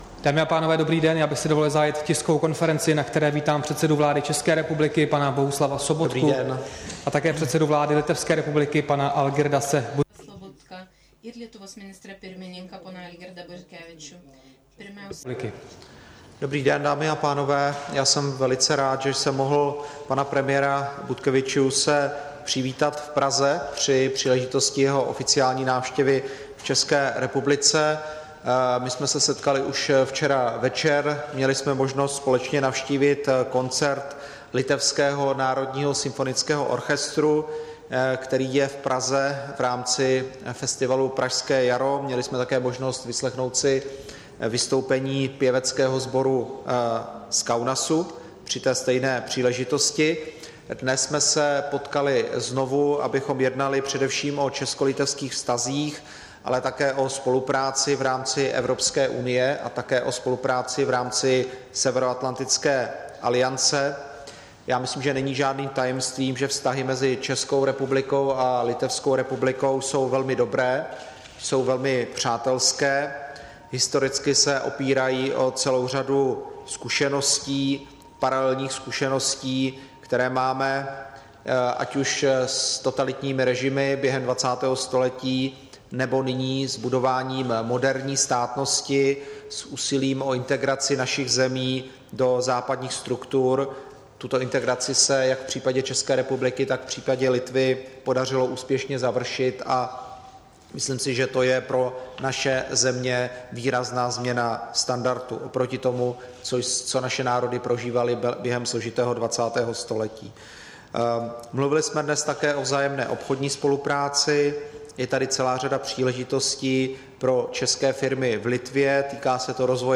Tisková konference po setkání premiéra Sobotky s předsedou vlády Litevské republiky Butkevičiusem, 24. května 2016